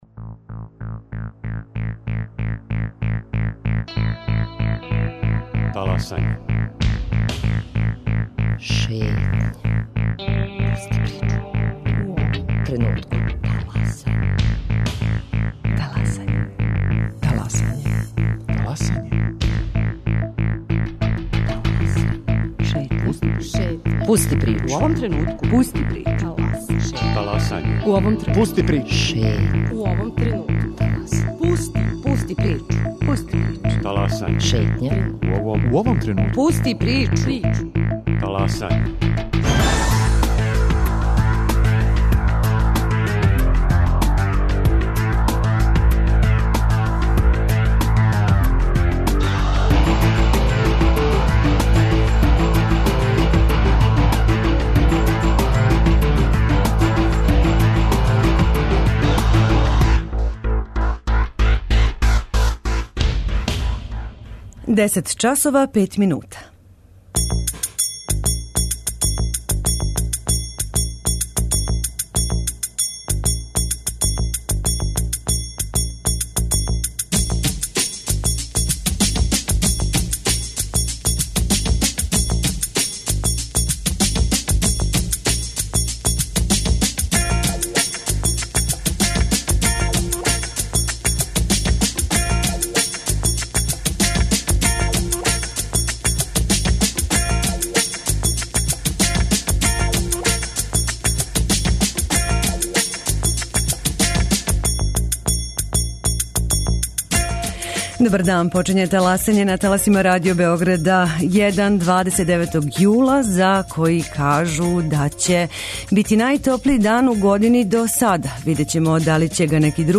На пролећној турнеји Радио Београда 1 по Бачкој, у Бачкој Тополи забележили смо разговор са Карољом Лацковићем, чланом Општинског већа задуженим за спорт, културу и информисање.